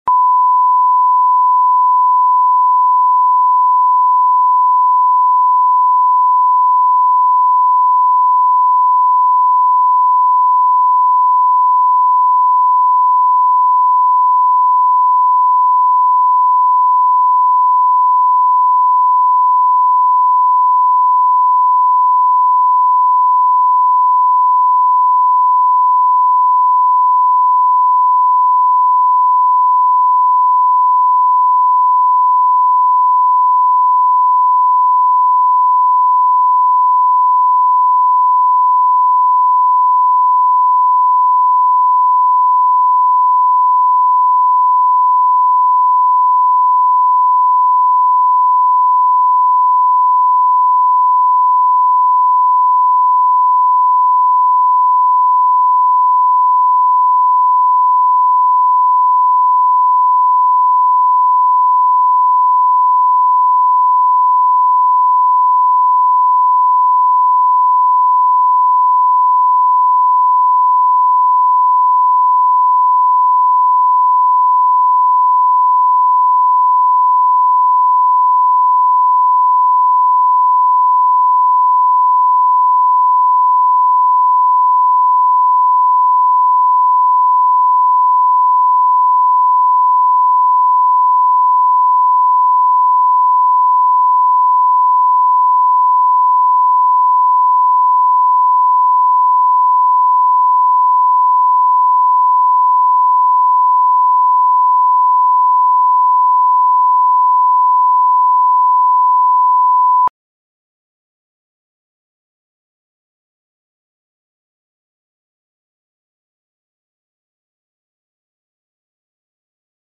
Аудиокнига Поворот